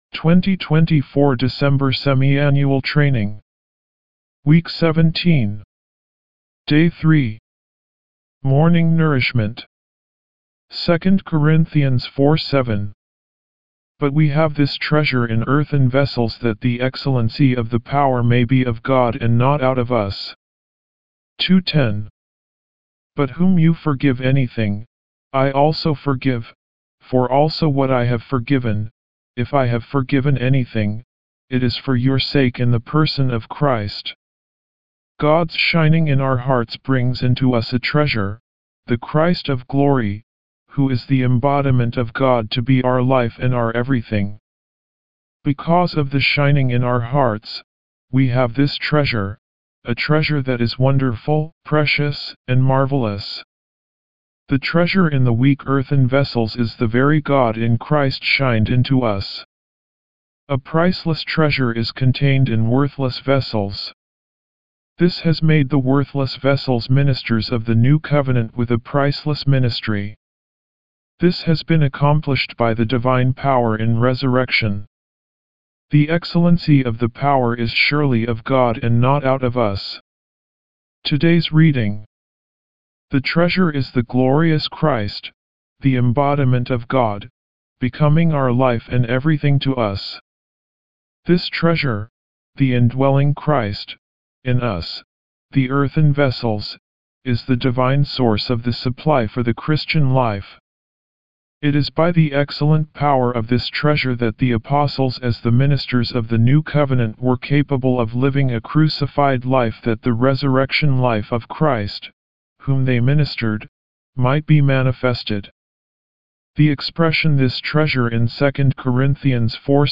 202307晨興聖言中英文朗讀
Morning Revival Recitation